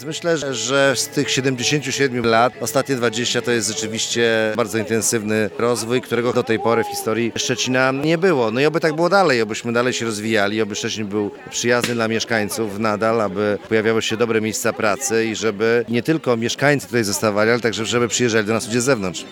Tak w Różanym Ogrodzie, mieszkańcy świętowali urodziny Szczecina.
Prezydent Piotr Krzystek podsumował ostatnie lata rozwoju stolicy Pomorza Zachodniego.